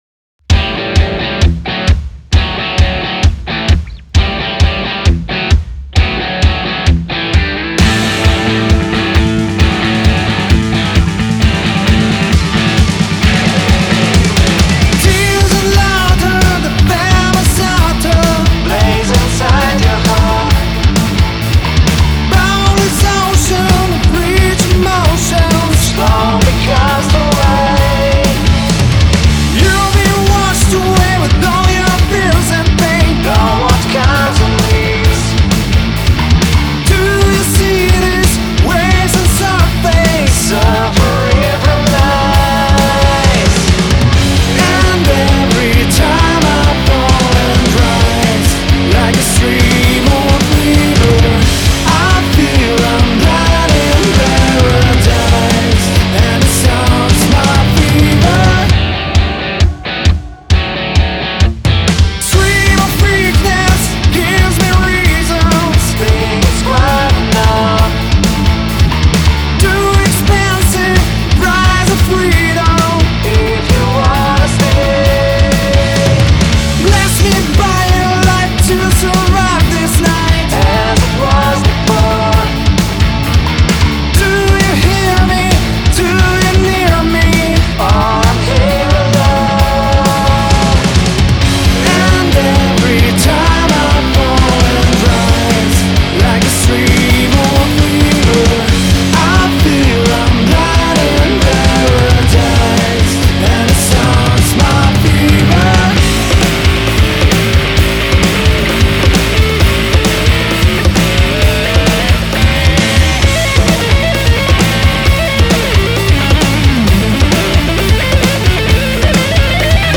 Песенка-ищу Звук гитар на припев.
Сорямба, что скачал мультитрек и покрутил его, хоть он и не мне предназначался, но больно уж песни у вас классные - не мог отказать себе в удовольстивии Вложения FEVER.mp3 FEVER.mp3 6,4 MB · Просмотры: 1.972